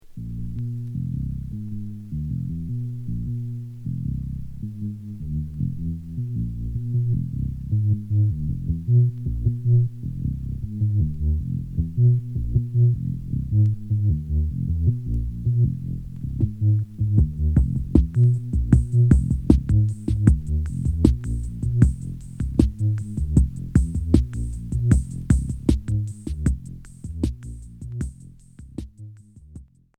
Expérimental Unique 45t retour à l'accueil